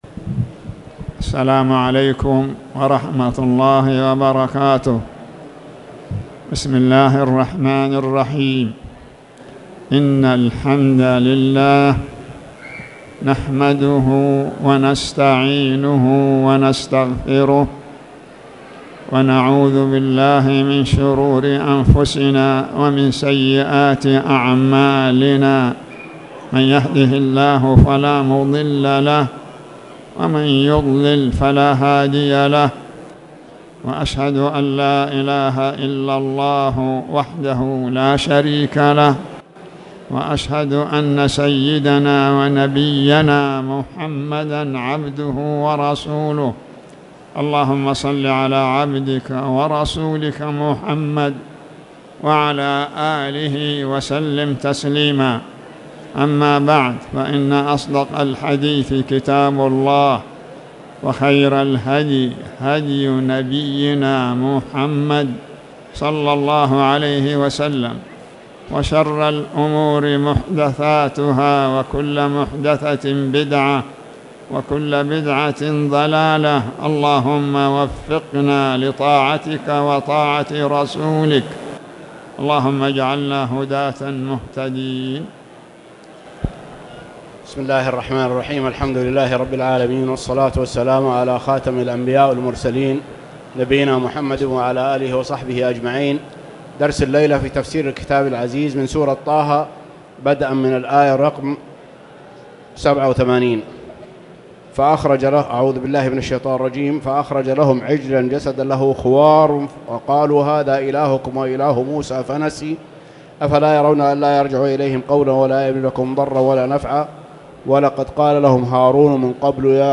تاريخ النشر ٢٢ رجب ١٤٣٨ هـ المكان: المسجد الحرام الشيخ